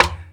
Snare (34).wav